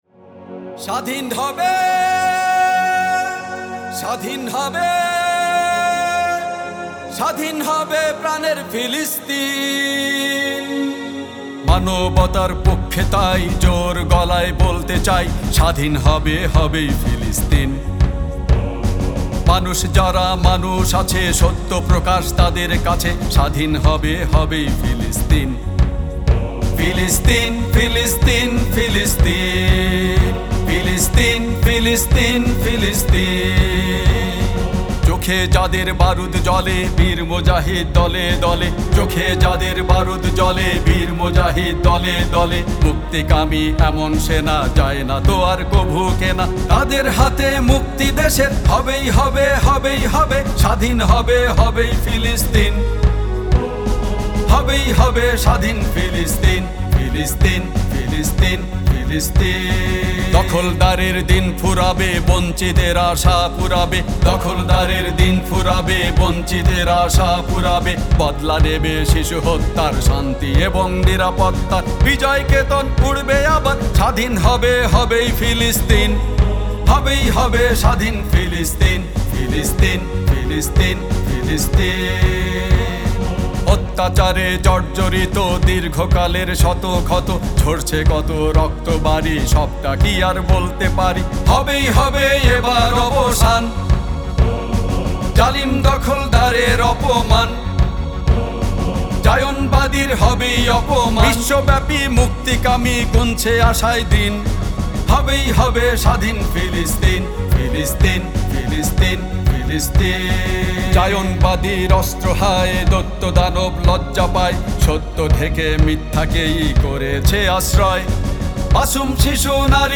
ফিলিস্তিনবিষয়ক গান: 'স্বাধীন হবে প্রাণের ফিলিস্তিন'
গানটিতে সুরারোপ এবং কণ্ঠ দিয়েছেন গীতিকার নিজেই।